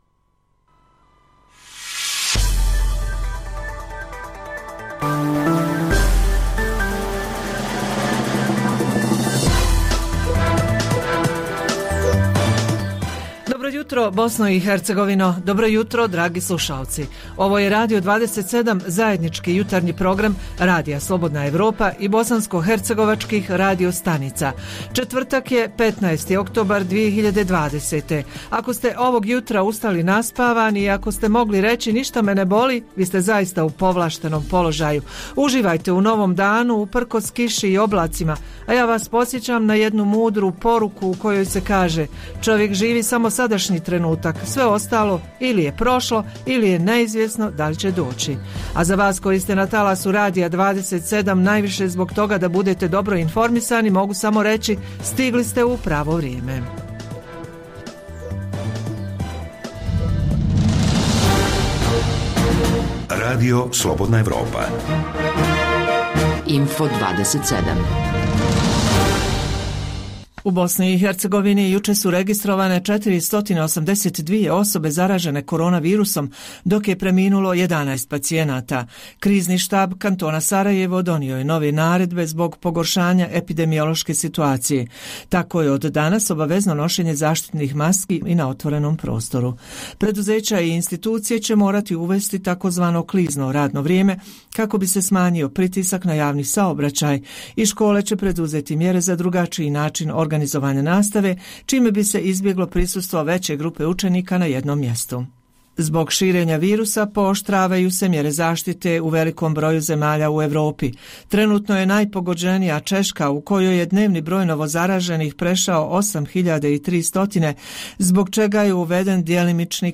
Emitujemo tri pregleda vijesti